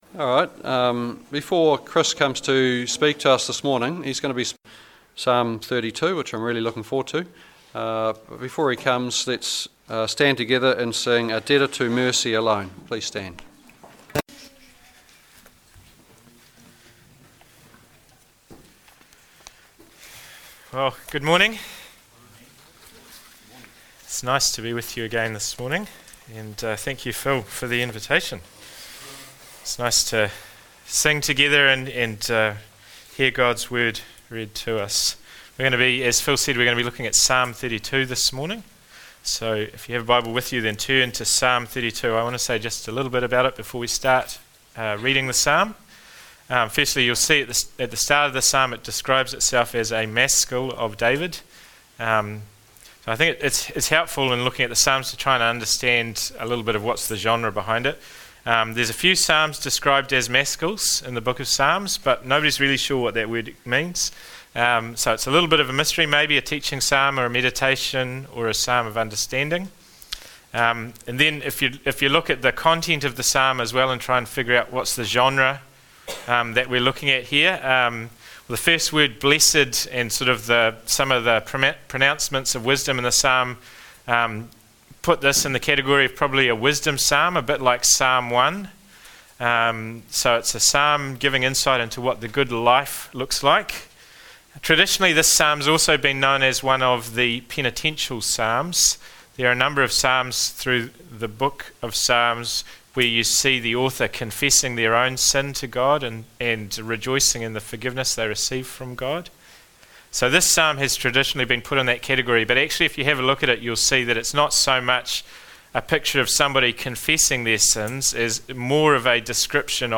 From Series: “Standalone Sermons“